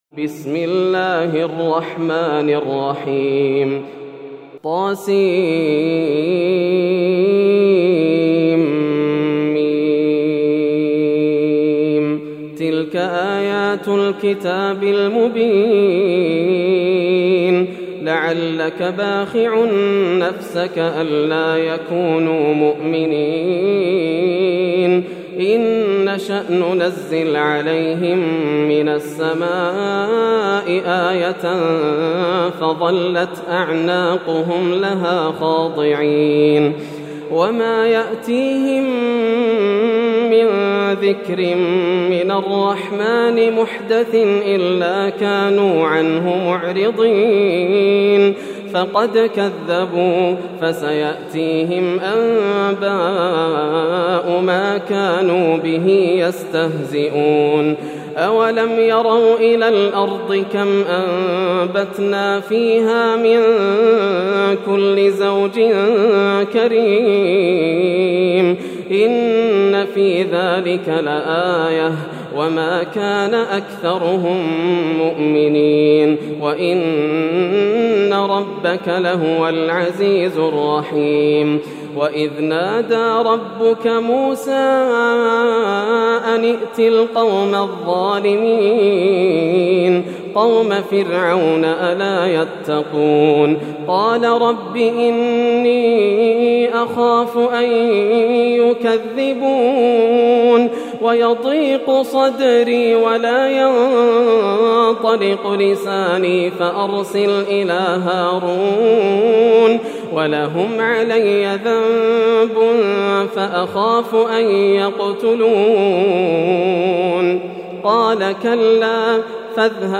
سورة الشعراء > السور المكتملة > رمضان 1431هـ > التراويح - تلاوات ياسر الدوسري